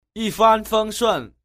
Yīfānfēngshùn.
y phan phâng xuân